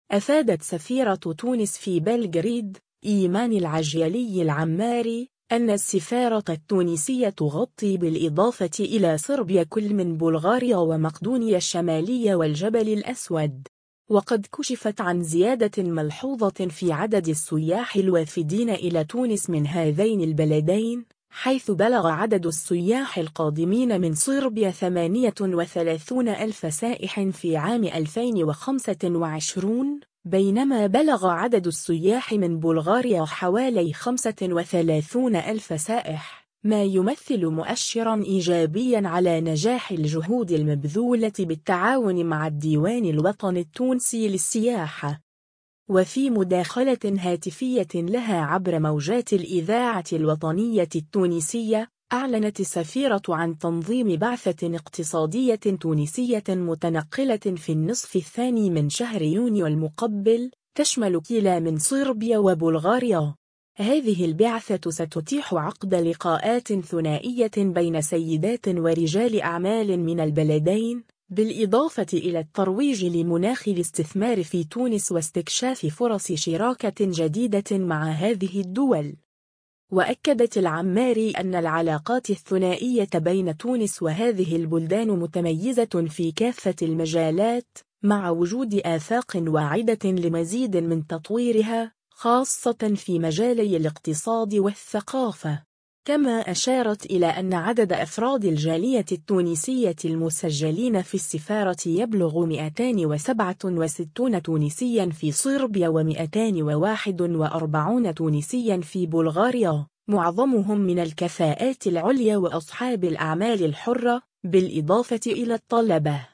وفي مداخلة هاتفية لها عبر موجات الإذاعة الوطنية التونسية، أعلنت السفيرة عن تنظيم بعثة اقتصادية تونسية متنقلة في النصف الثاني من شهر يونيو المقبل، تشمل كلًا من صربيا وبلغاريا.